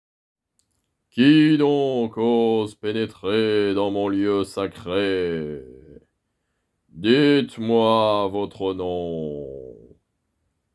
Voix off
Voix grave